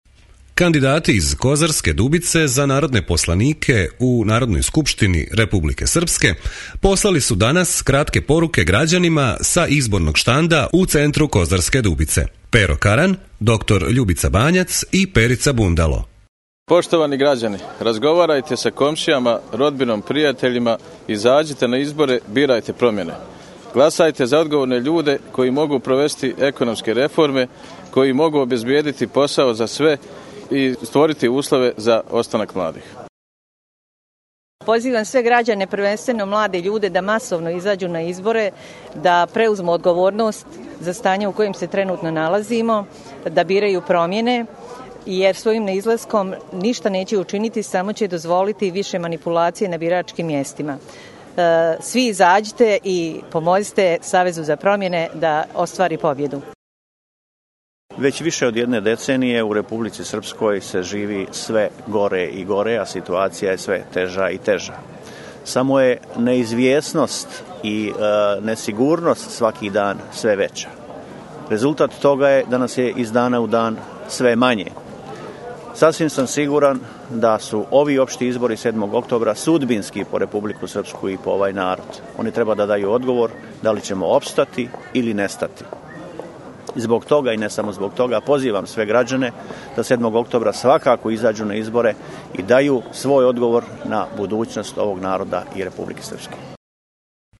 Поруке кандидата из Коз. Дубице са изборног штанда